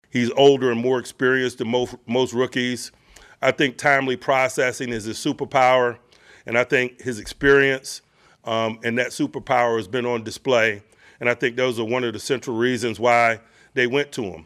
Tomlin held his weekly media event yesterday, and said his sole focus is on the Browns, not on the fact that the Steelers will play two AFC North rivals in five days…Cleveland on Sunday and Cincinnati on Thursday, October 16th.